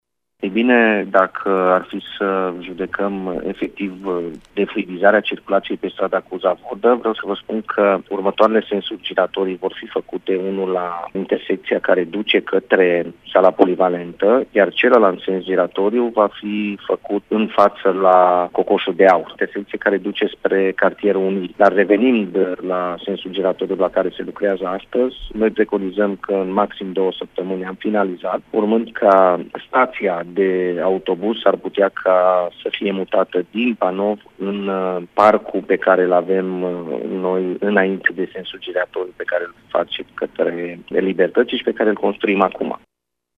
Este vorba de intersecţia de la Sala Polivalentă şi cea de pe strada Călăraşilor în zona Cocoşul de Aur, a explicat viceprimarul municipiului Tg.Mureș, Claudiu Maior: